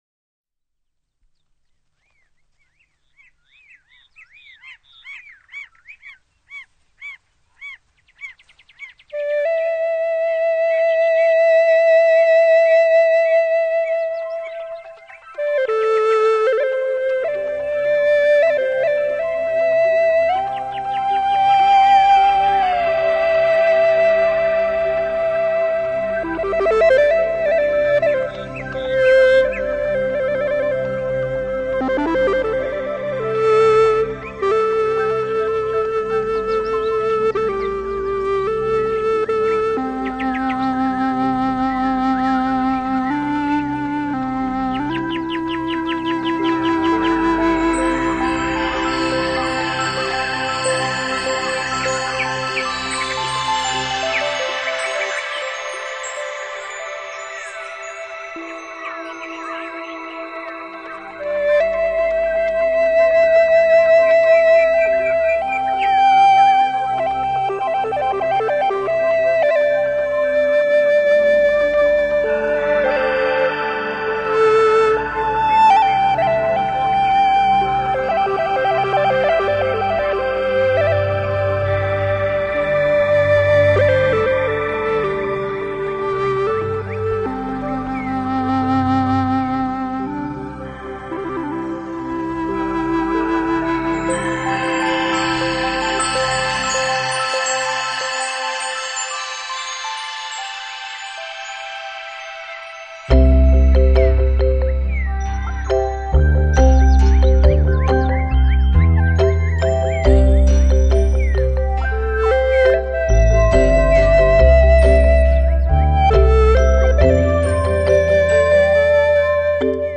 遠在大漠的哈薩克人，則吹起了牧笛、彈起了冬不拉（哈薩克最流行的彈奏樂器）， 就像要把大漠的秋霞，織錦的更美麗....